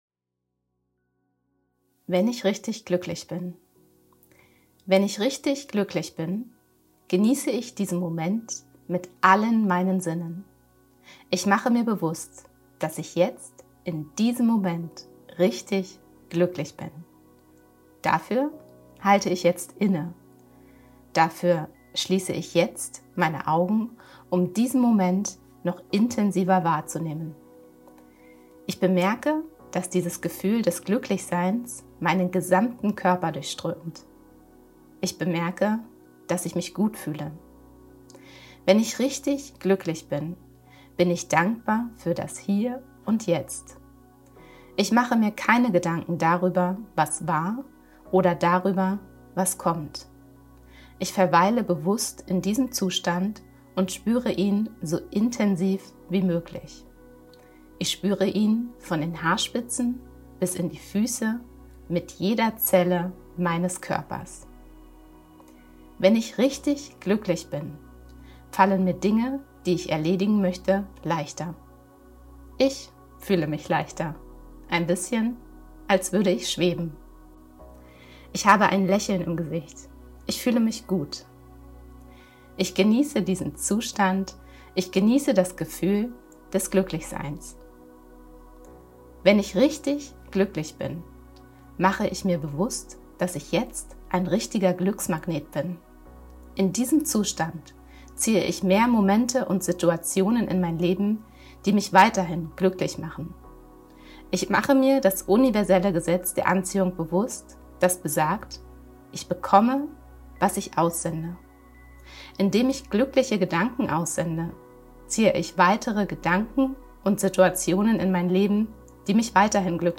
Wenn-ich-richtig-gluecklich-bin_mit-Musik.mp3